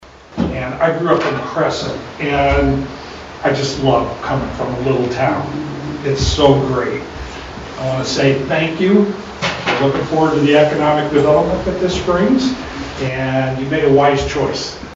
Neola Mayor Karla Pogge and Pottawattamie County Supervisor Brian Shea extended warm greetings at HTS AG’s grand opening and ribbon-cutting ceremony.